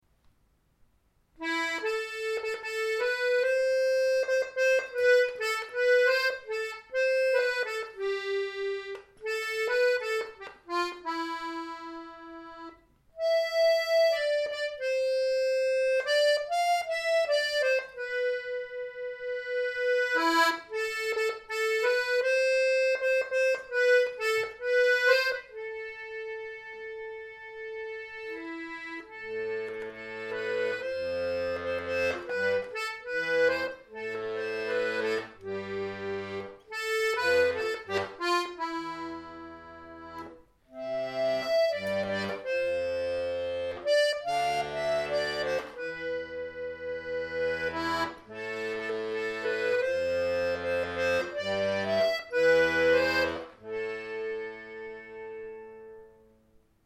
Gallic (traditionnel de l'île de Groix